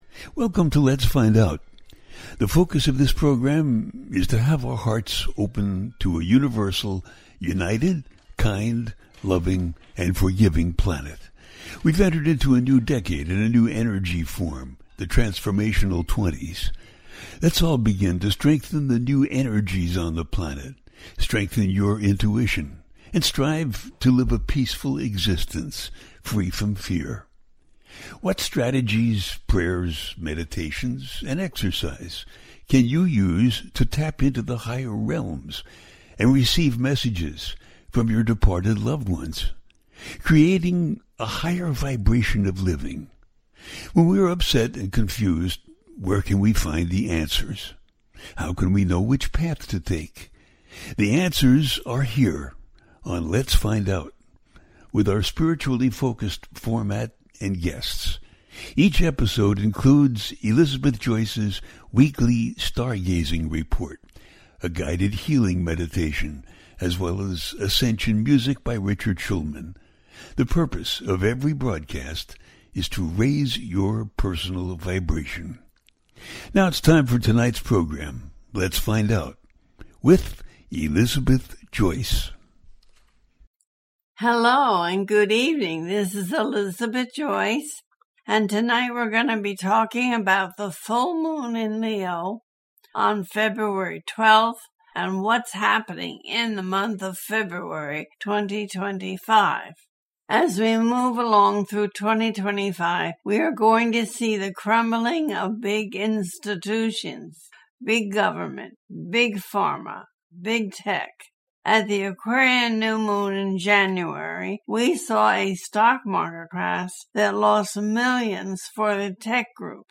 The Full Moon in Leo and What 2025 Holds - A teaching show
The listener can call in to ask a question on the air.
Each show ends with a guided meditation.